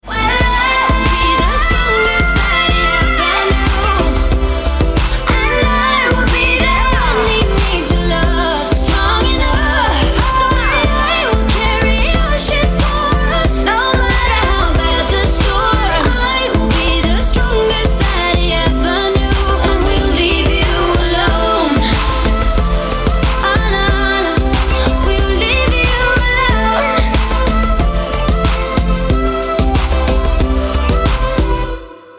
• Качество: 320, Stereo
поп
женский вокал
dance
красивый женский голос